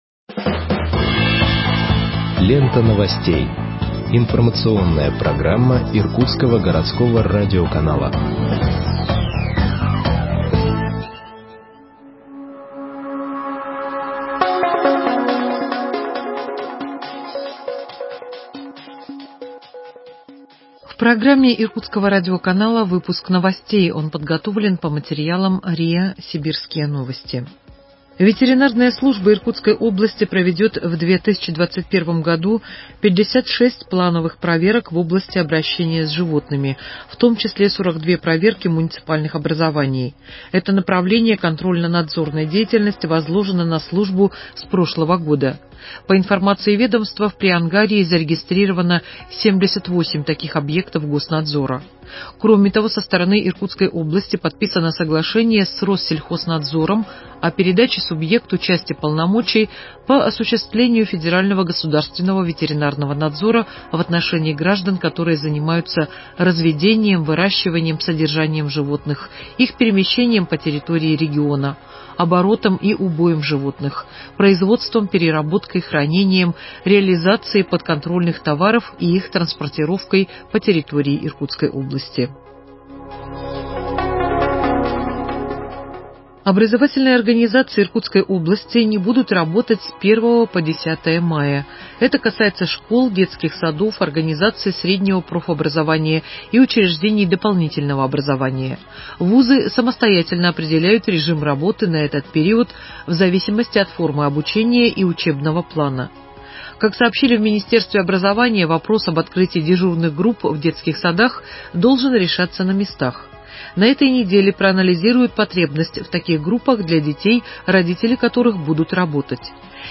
Выпуск новостей в подкастах газеты Иркутск от 29.04.2021 № 1